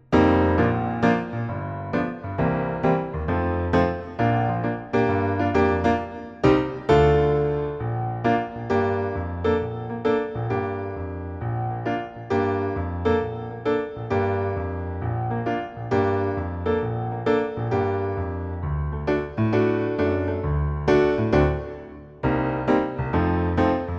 No Vocals At All Soundtracks 2:25 Buy £1.50